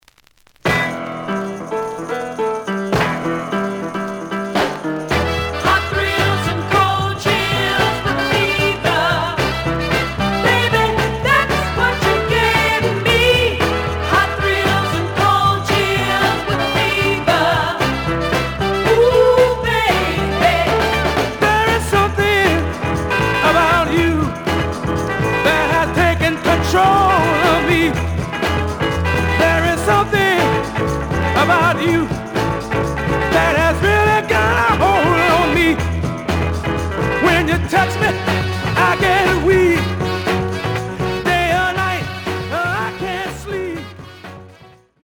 The audio sample is recorded from the actual item.
●Genre: Funk, 60's Funk
Slight edge warp.